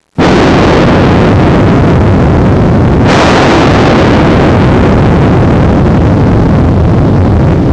Large 8 bit explosion
You can try to create some 8 bit sounds on a synthesizer. Grab any synth (with a noise oscillator), play a bit with the envelope, add bitcrushing, effects, more effects and voila!